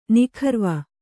♪ nikharva